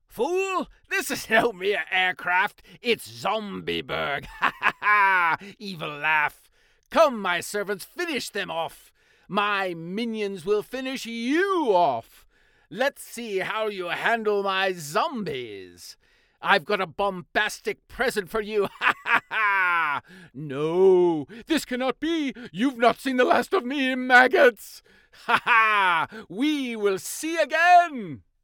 Cartoon & Character Voice Overs | The Voice Realm